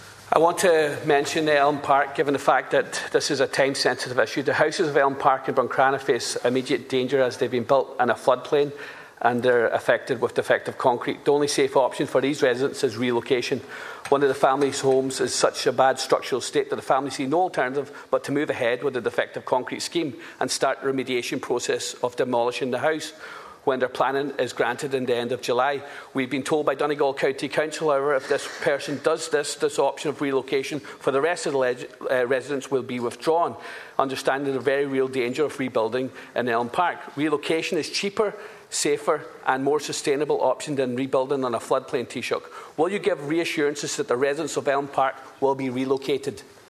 Speaking in the Dail yesterday, 100% Redress Party’s Charles Ward said the houses have been built on a flood plain and are affected by defective concrete.